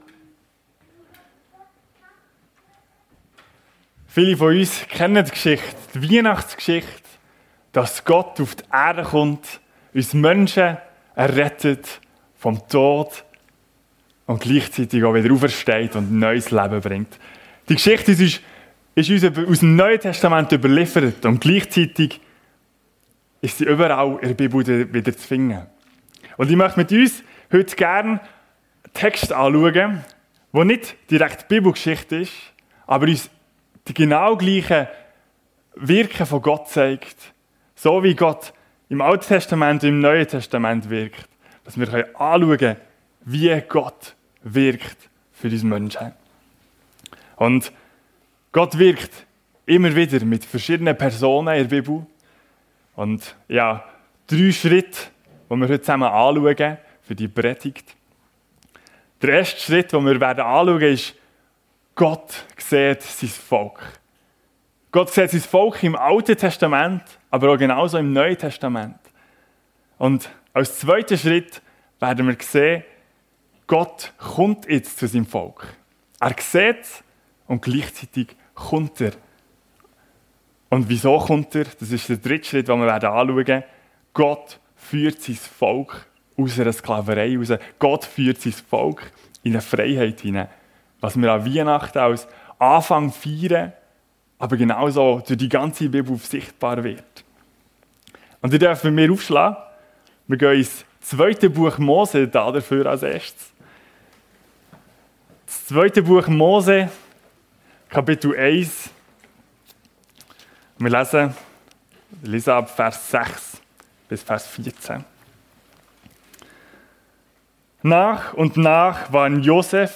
Weihnachten ~ FEG Sumiswald - Predigten Podcast